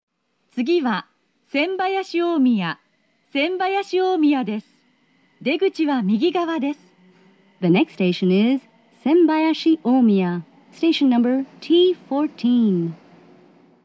大阪市営地下鉄谷町線車内放送・駅放送-SAS
谷町線車内放送2010